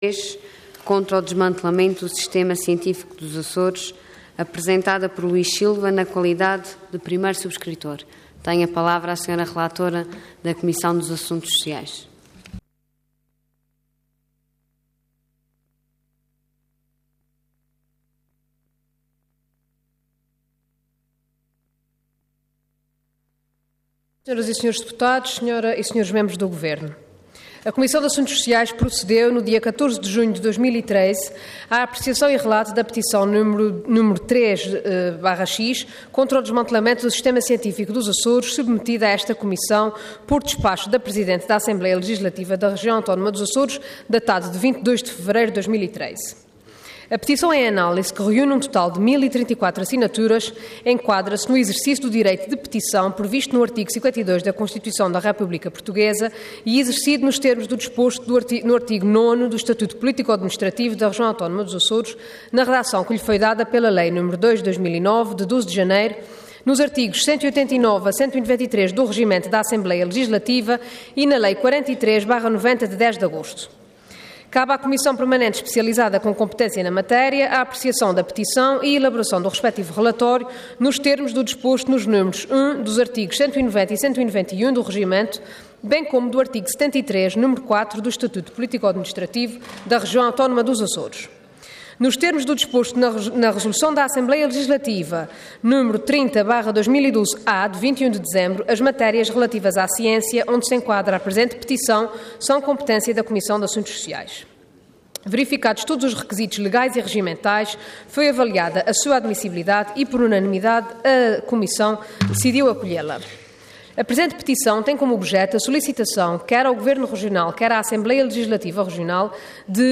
Detalhe de vídeo 3 de setembro de 2013 Download áudio Download vídeo Diário da Sessão Processo X Legislatura Contra o desmantelamento do sistema cientifíco dos Açores. Intervenção Petição Orador Renata Correia Botelho Cargo Deputada Entidade Comissão de Assuntos Sociais